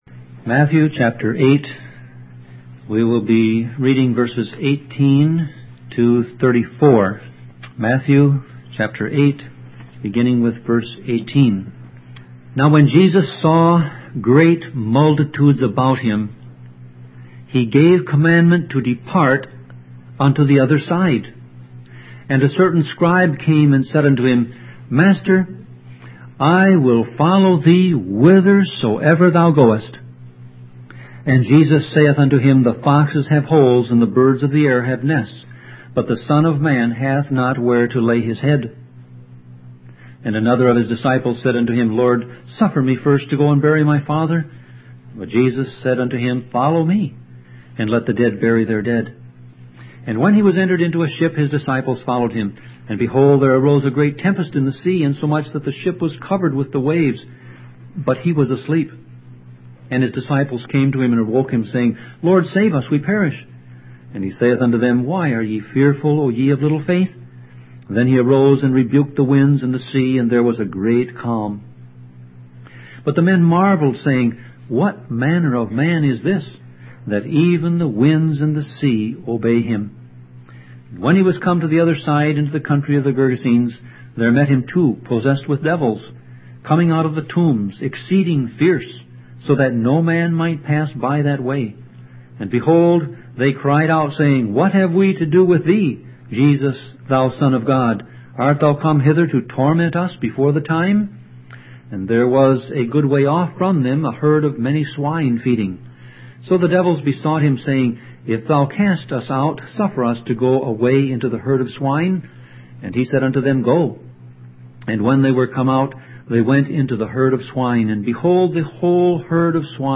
Sermon Audio Passage: Matthew 8:18-34 Service Type